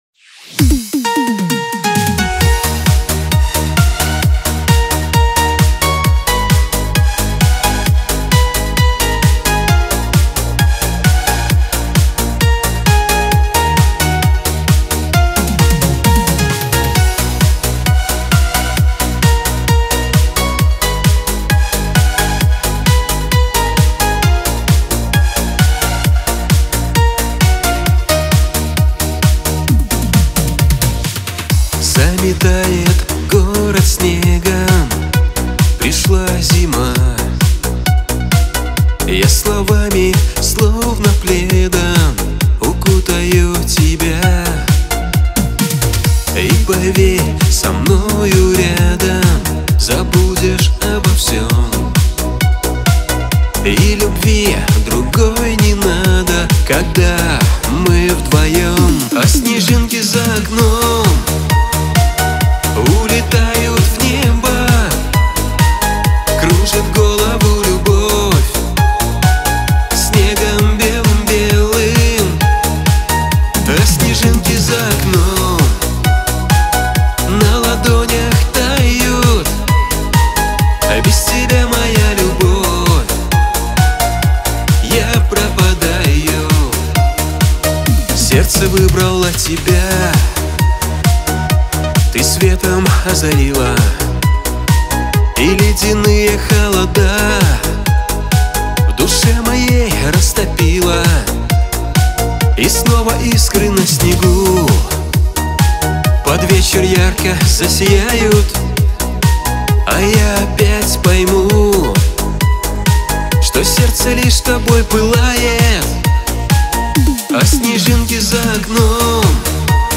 Русская AI музыка